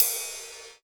VEC3 Cymbals Ride 23.wav